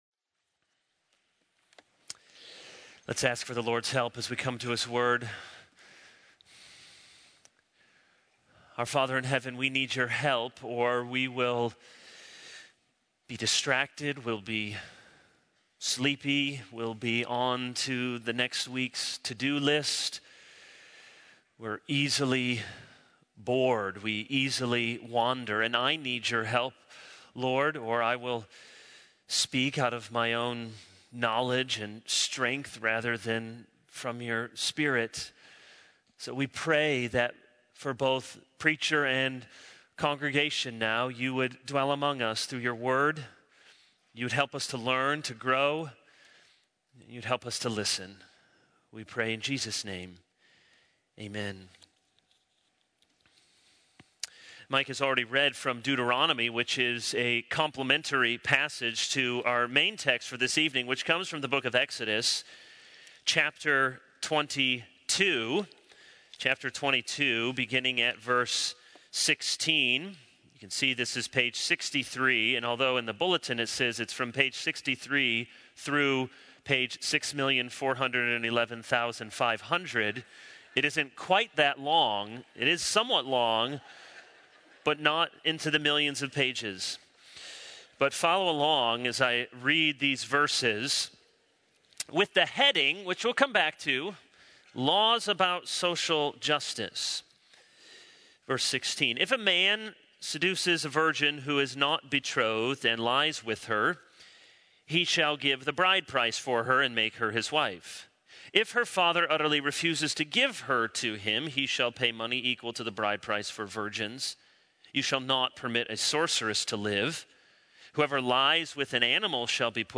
This is a sermon on Exodus 22:16-23:19.